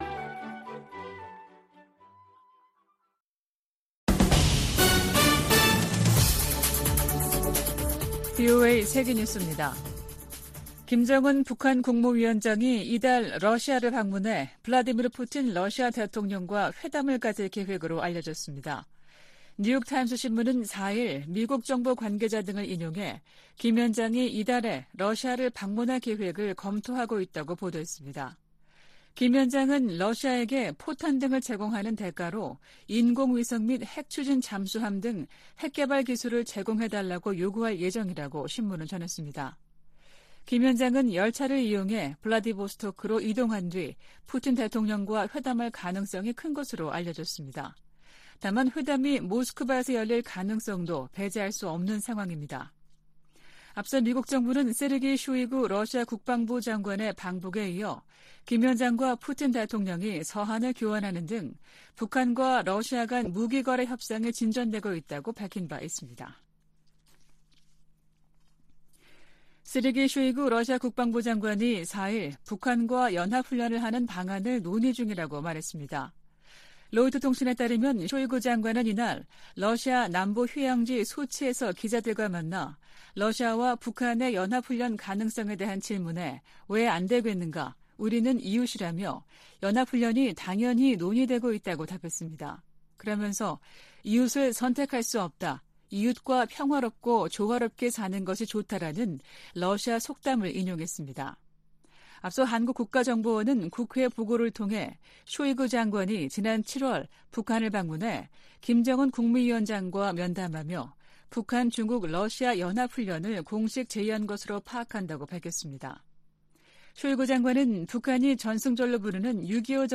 VOA 한국어 아침 뉴스 프로그램 '워싱턴 뉴스 광장' 2023년 9월 5일 방송입니다. 북한이 전략순항미사일을 발사하며 핵 공격 능력을 과시하려는 도발을 이어갔습니다. 러시아가 북한에 북중러 연합훈련을 공식 제의했다고 한국 국가정보원이 밝혔습니다. 백악관은 바이든 행정부가 인도태평양 지역을 중시하고 있다고 거듭 강조했습니다.